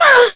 jess_hurt_06.ogg